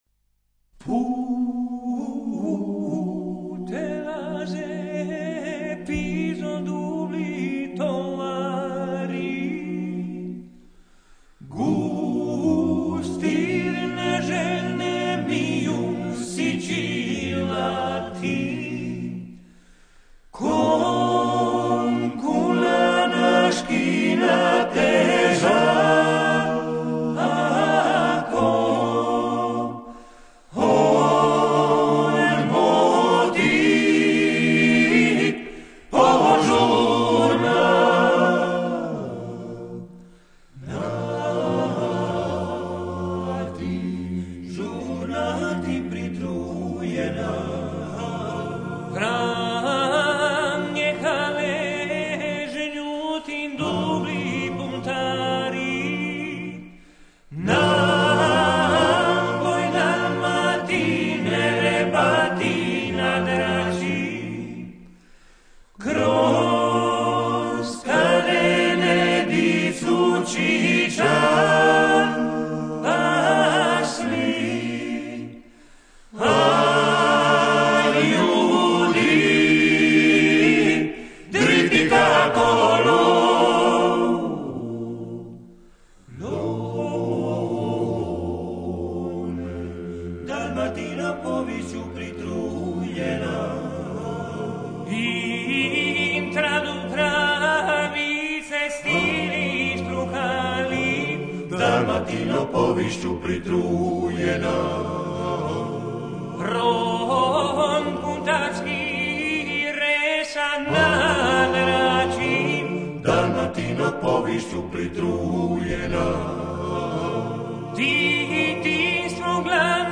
Composed Dalmatian song